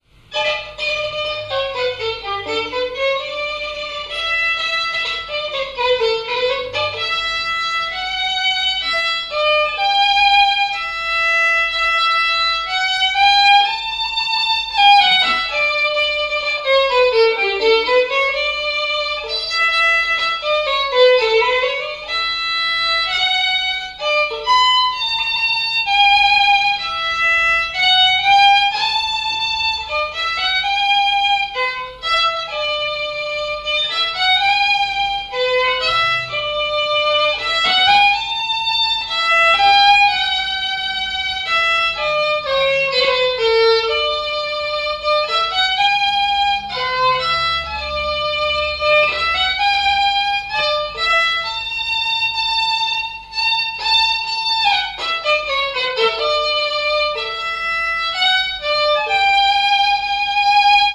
danse : marche
Genre strophique
répertoire de bals et de noces